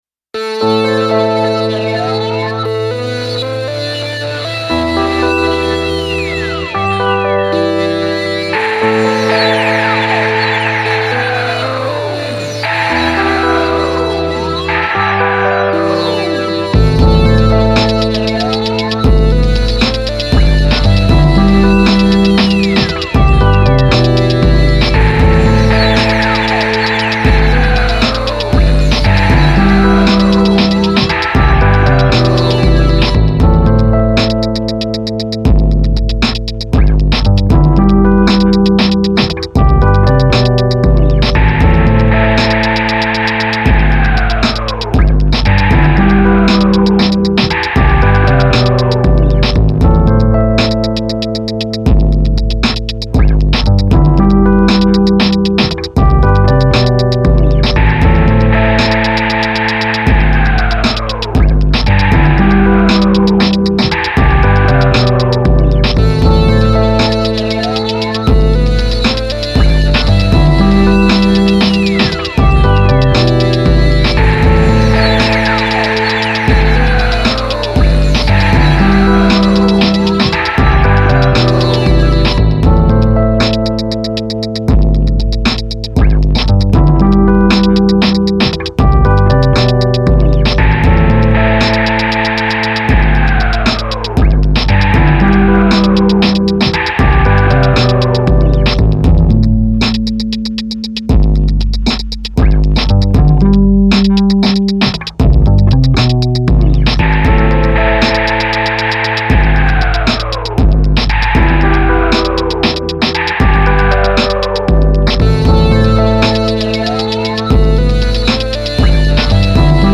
14 new instrumentals for you to rock over.